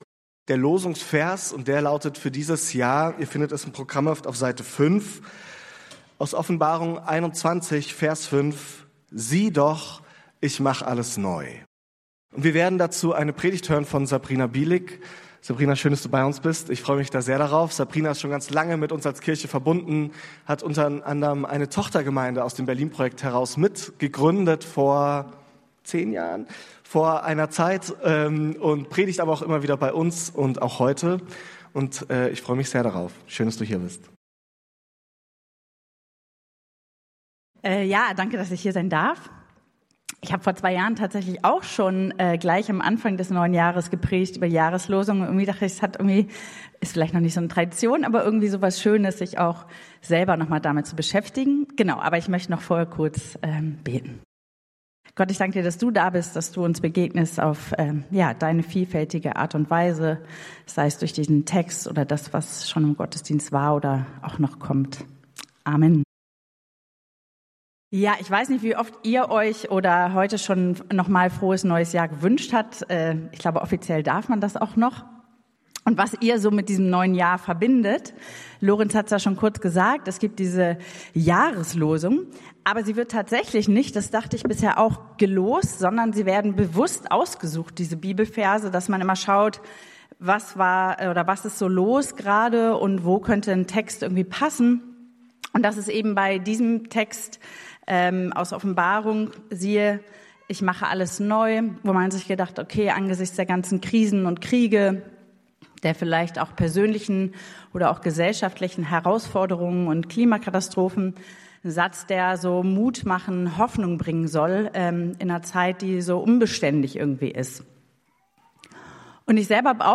Raus aus der Schleife! ~ Berlinprojekt Predigten Podcast